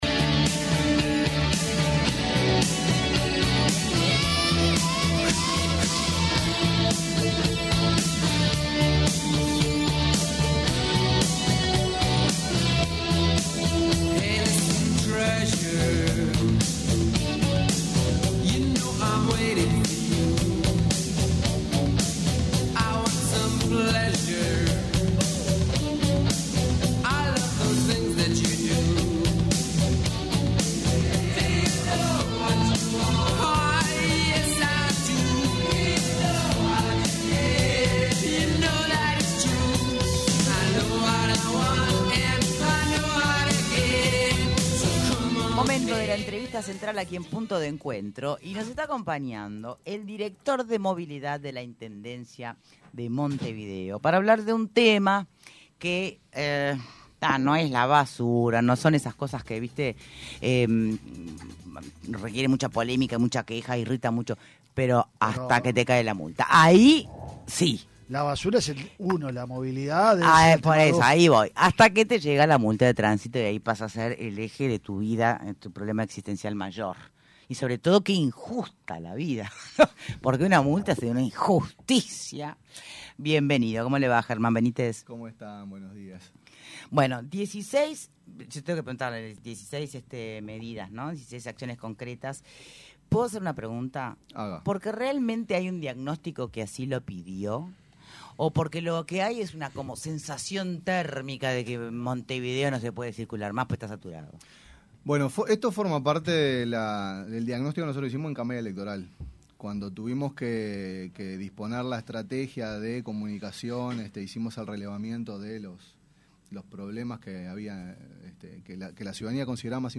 El director de Movilidad de la Intendencia de Montevideo, Germán Benítez dijo en entrevista con Punto de Encuentro que el transporte público debe apostar a resolver los viajes “homogéneos” de las personas en el día a día y se puso de ejemplo, al decir que algunos días utiliza auto y otros va en ómnibus.